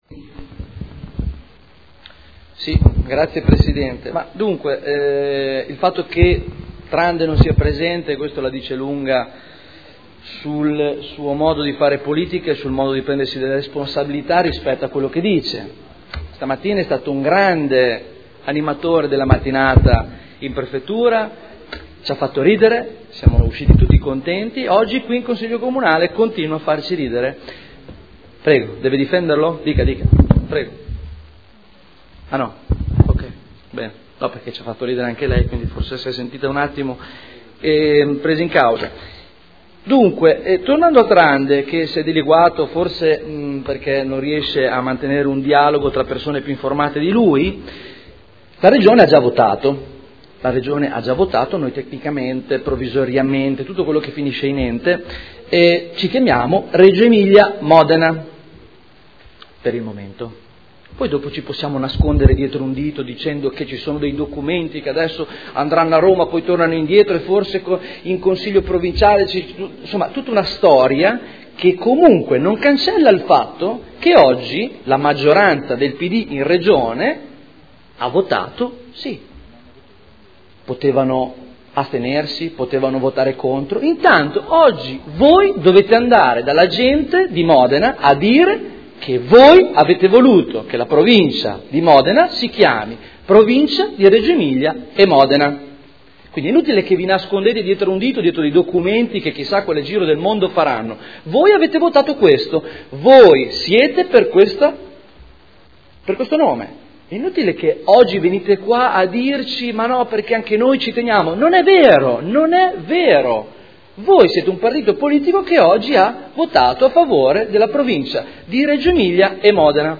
Stefano Barberini — Sito Audio Consiglio Comunale